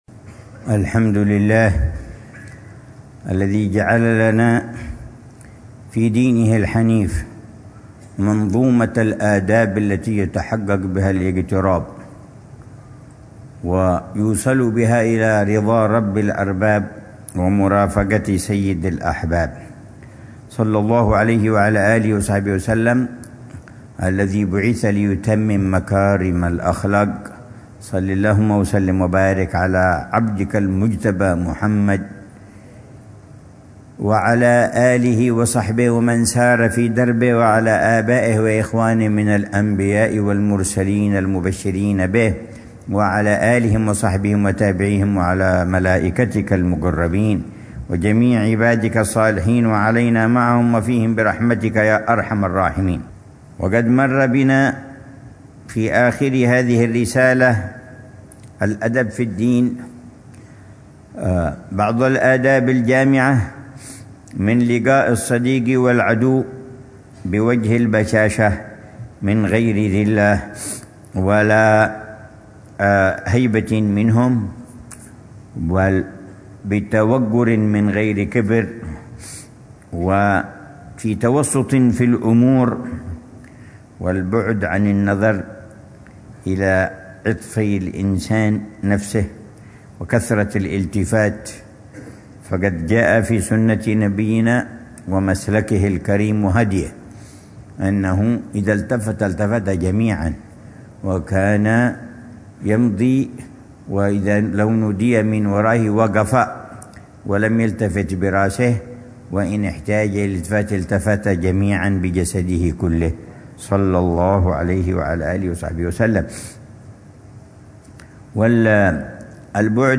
الدرس الثامن والأربعون من شرح العلامة الحبيب عمر بن حفيظ لكتاب الأدب في الدين لحجة الإسلام الإمام محمد بن محمد الغزالي، ضمن الدروس الصباحية لأ